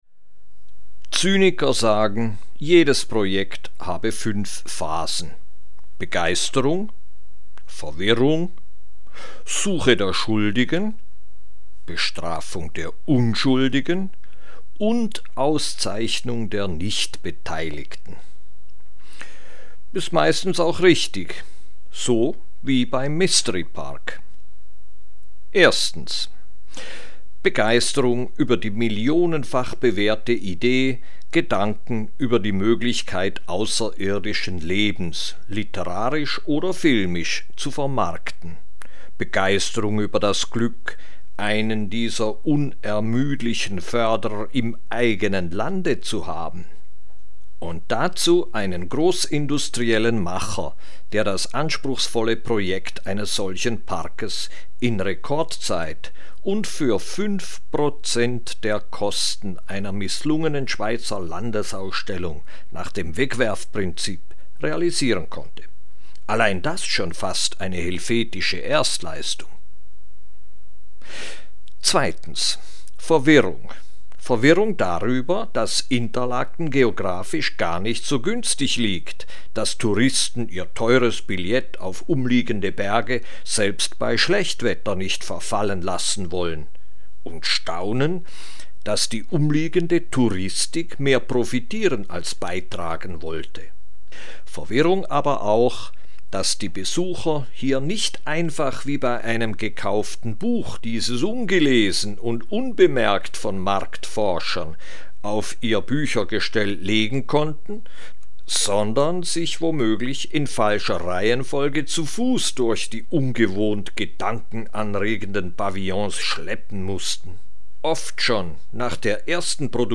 Eine Glosse über die weitgehend unverdienten Probleme des Mysteryparkes. Wer so oft über das Thema Auskunft geben musste wie ich, bekommt automatisch Lust, seine Gedanken ein für allemal in einem Vierminuten-Audiofile zusammenzufassen, um künftig zeit- und nervenschonend bis zum Gewinn neuer Weisheiten darauf verweisen zu können.